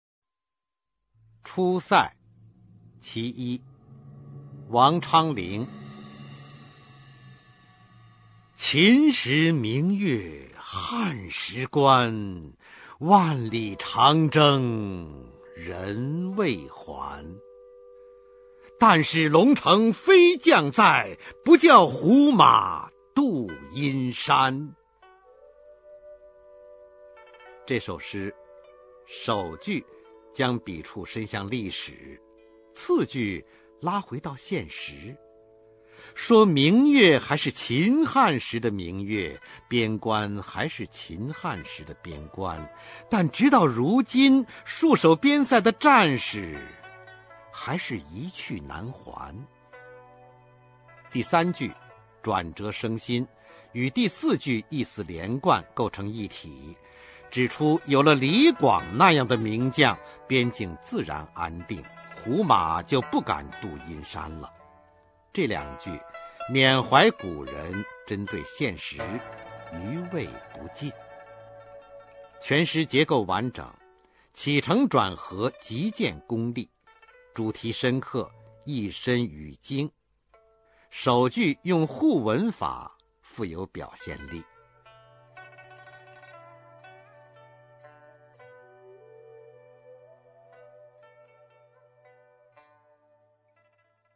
[隋唐诗词诵读]王昌龄-出塞（男） 配乐诗朗诵